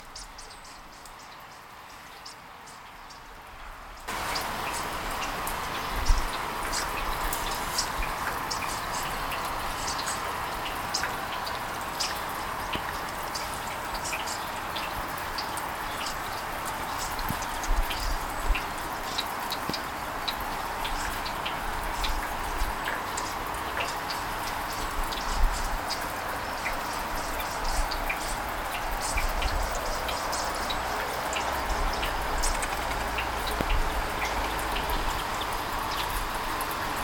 Sturnus vulgaris vulgaris
field recording